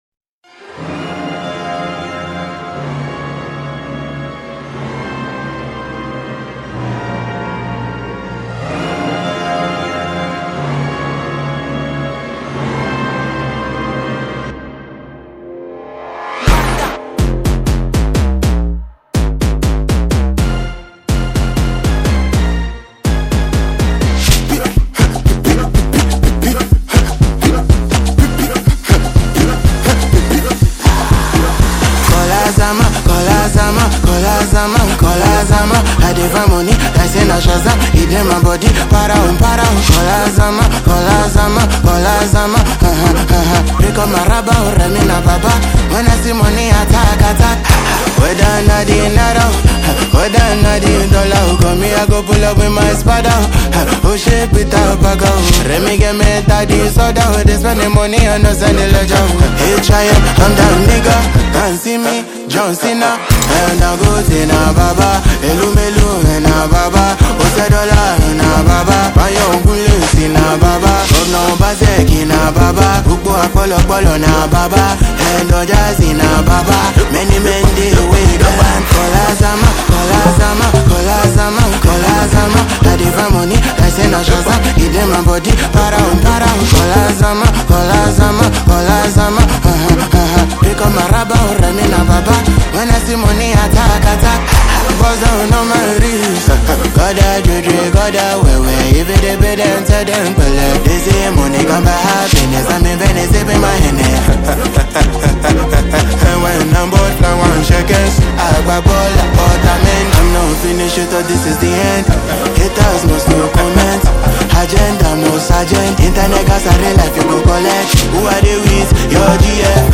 is a vibrant and energetic track